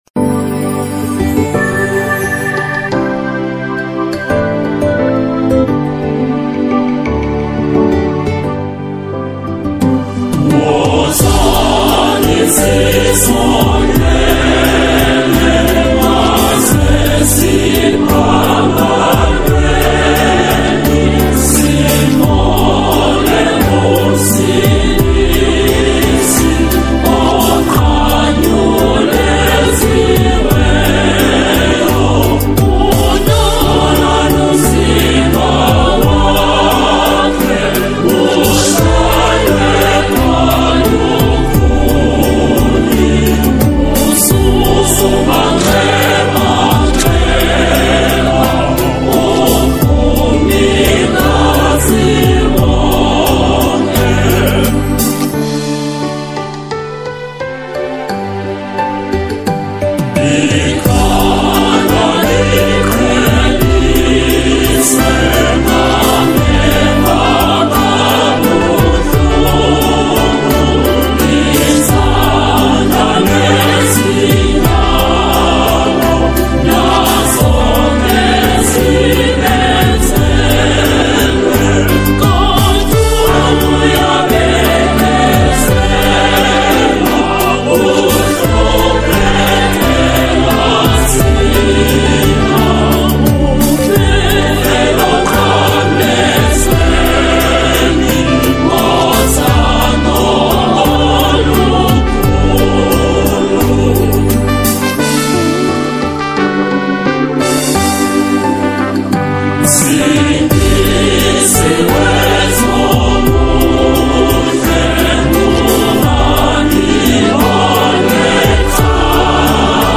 is a Zulu hymn